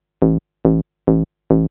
SNTHBASS020_DANCE_140_A_SC3.wav